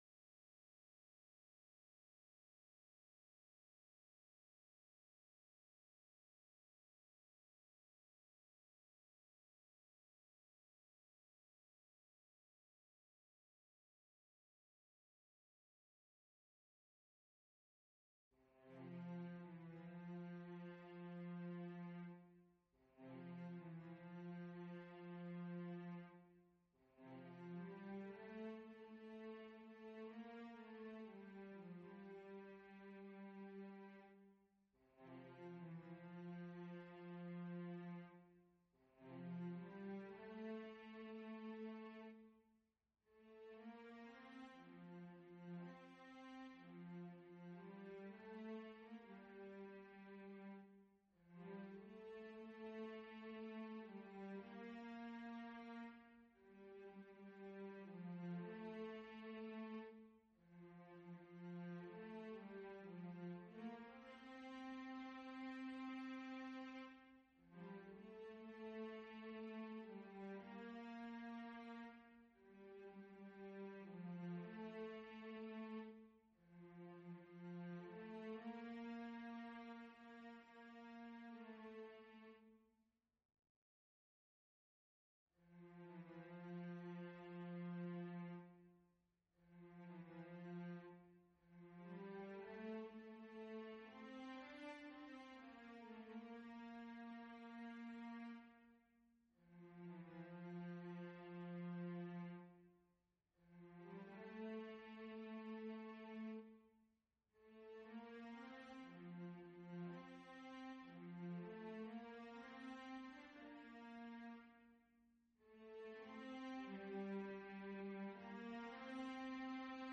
Bass Autumn 2025